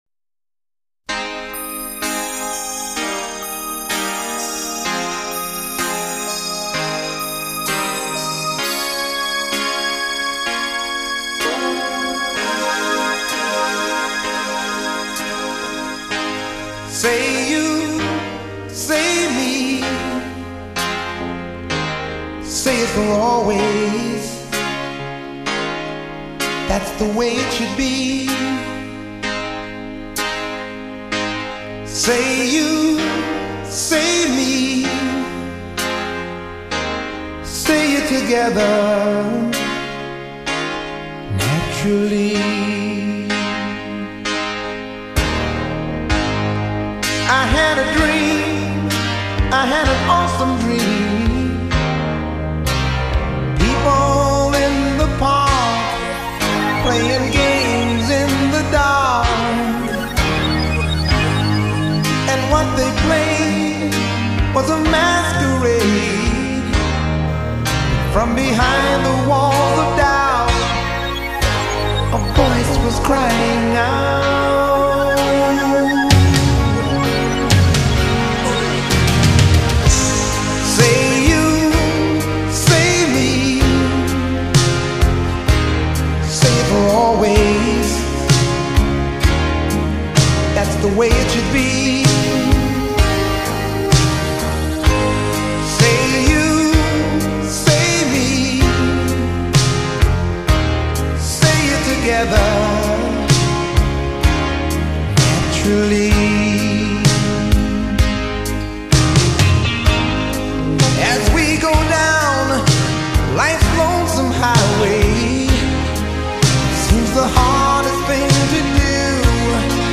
Surround7.1三维环绕HI—FI最经典人声高临场感音效。